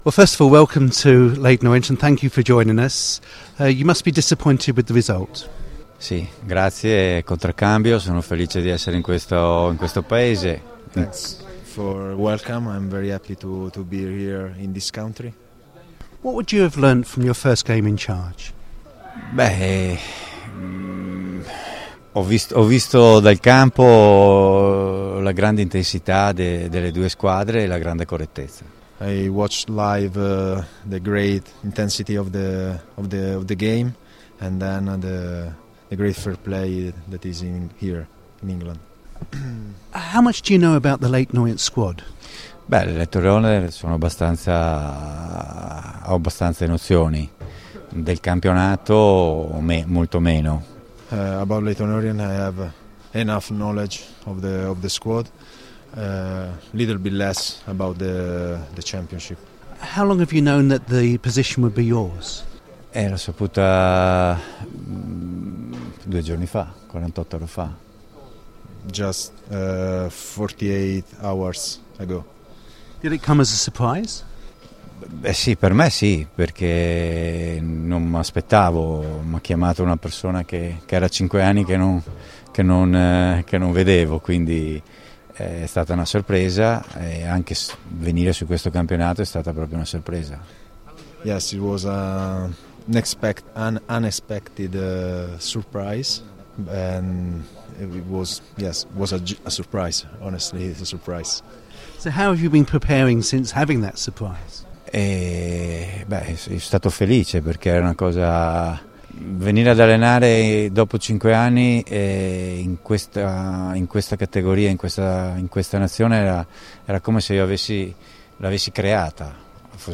FULL-INTERVIEW
through a translator